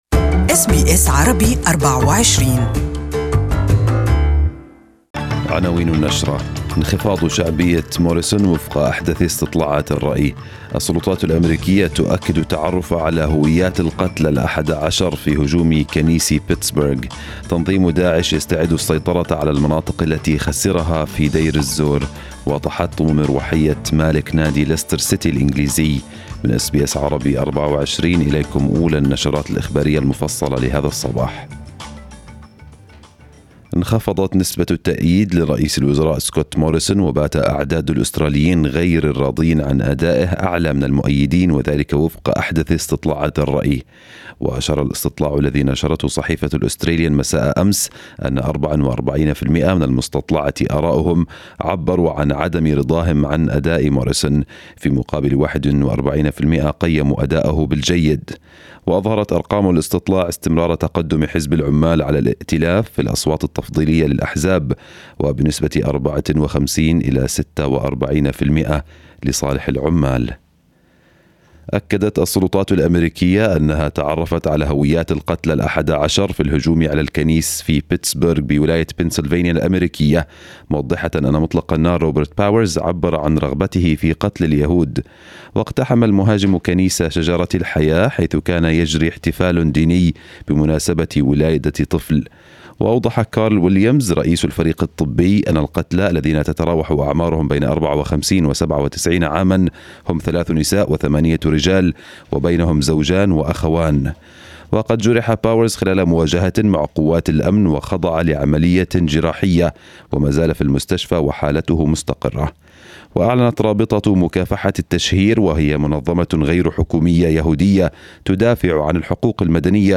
News bulletin for the day